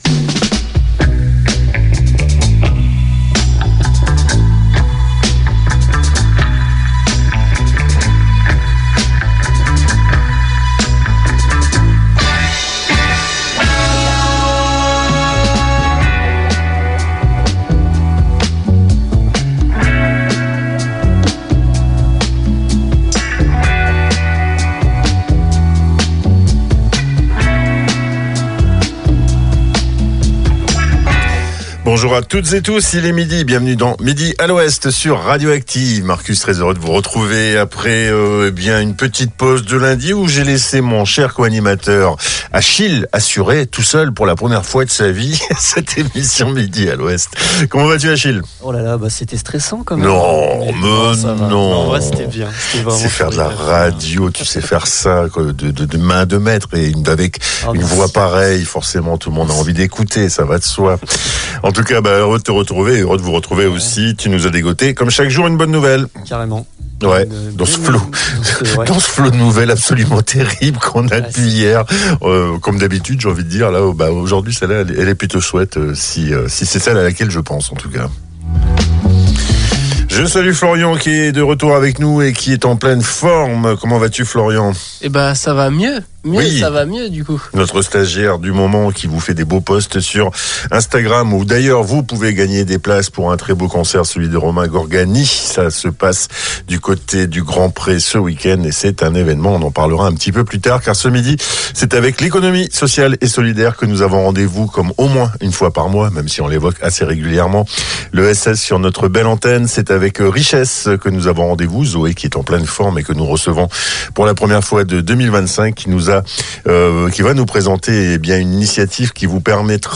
Et cette fois-ci, c'était au tour de l'Eclosion# ! Voici le Podcast de l'émission.